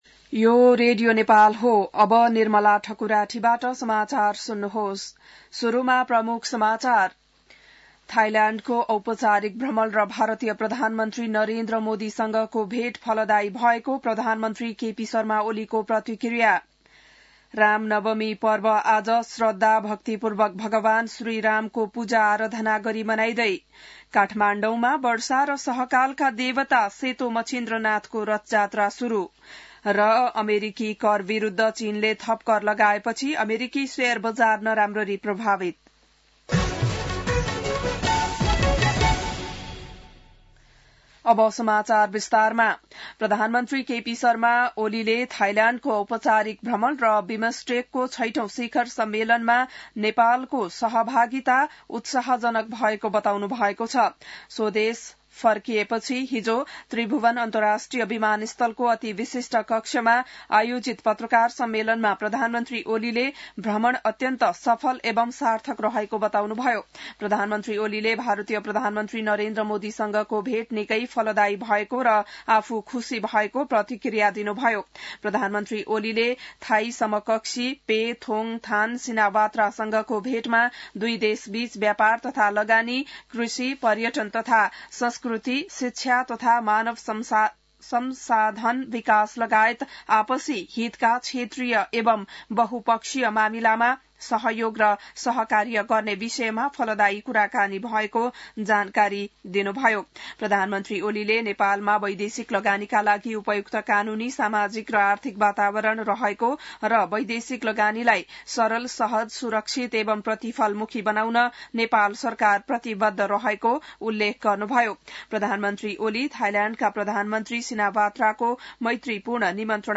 An online outlet of Nepal's national radio broadcaster
बिहान ९ बजेको नेपाली समाचार : २४ चैत , २०८१